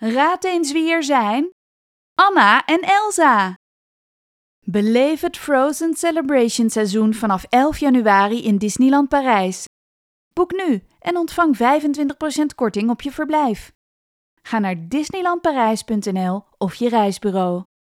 Op zoek naar een vrouwelijke voice-over met energie en overtuiging?
referentie-demo